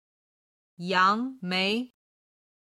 今日の振り返り！中国語音声